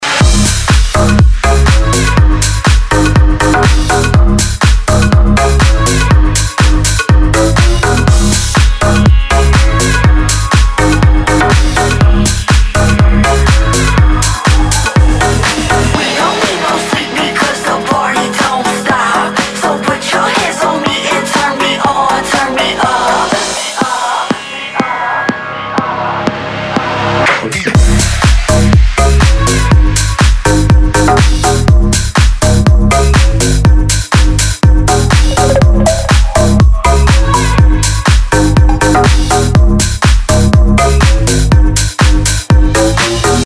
громкие
dance
future house
club
Отличный клубный рингтон!